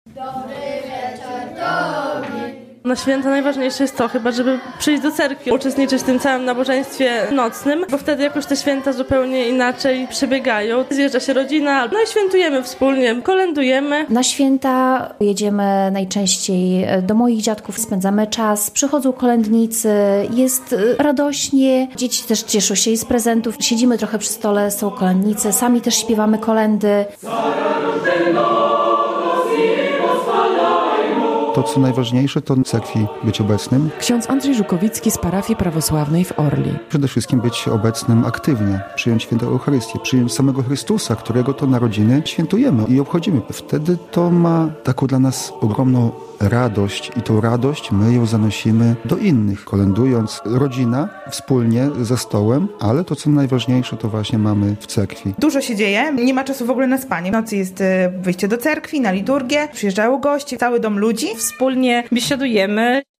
Jak prawosławni świętują Boże Narodzenie? - relacja